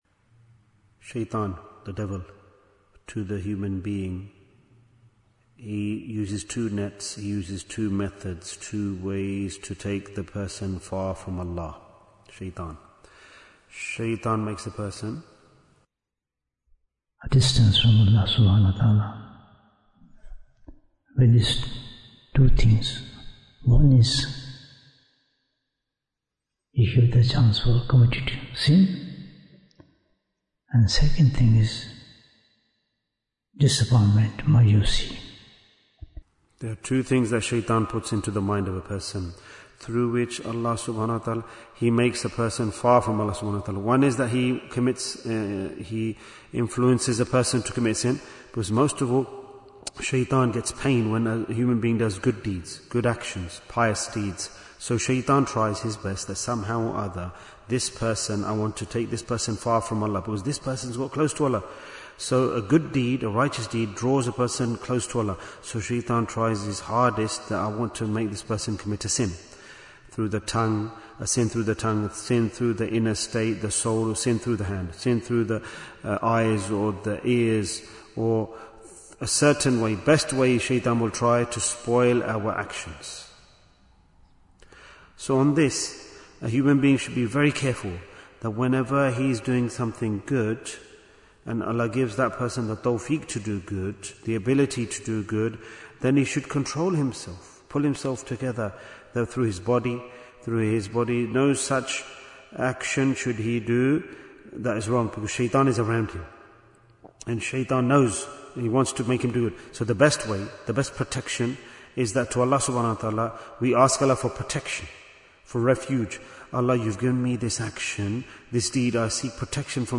Why is Tazkiyyah Important? - Part 24 Bayan, 26 minutes28th March, 2026